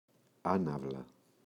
άναυλα [‘anavla]